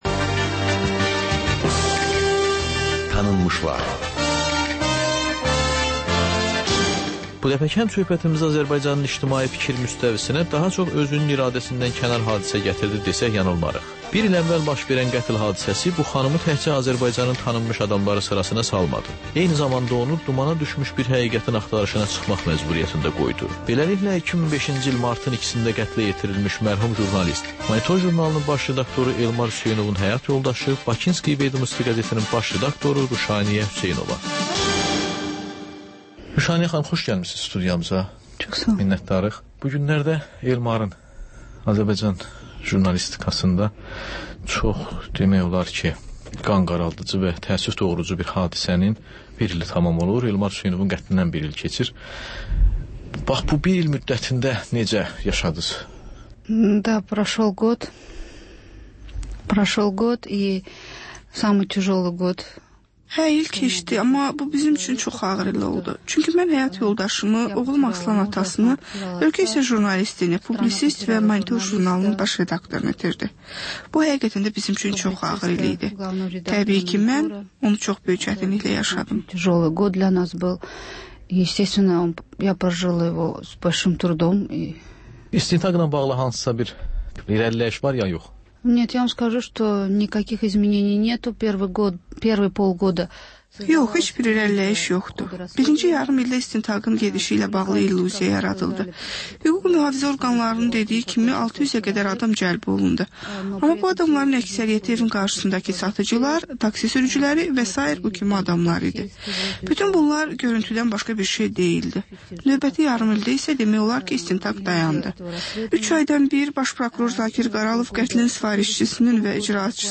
Həftə boyu efirə getmiş CAN BAKI radioşoularında ən maraqlı məqamlardan hazırlanmış xüsusi buraxılış (TƏKRAR)